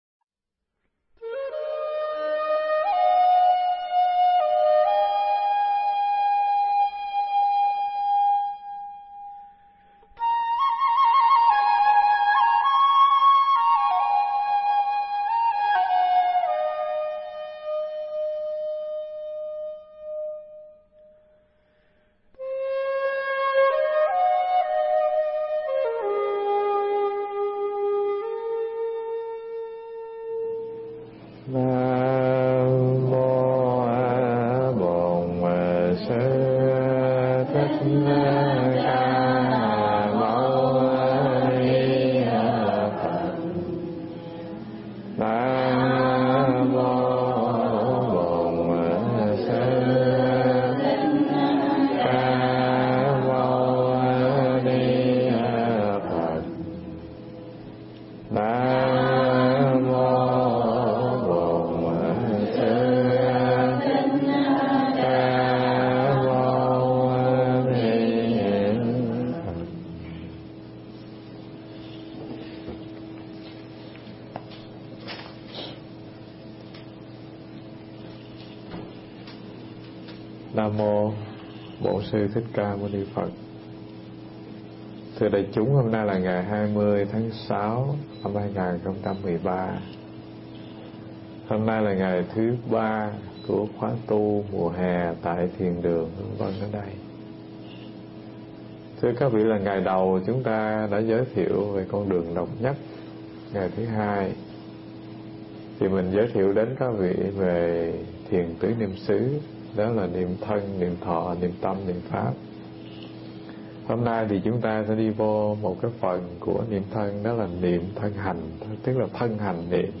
Nghe Mp3 thuyết pháp Niệm Thân Hành Phần 3